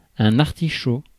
Prononciation
PrononciationFrance : « un artichaut »:
• IPA: [œ̃.n‿aʁ.ti.ʃo]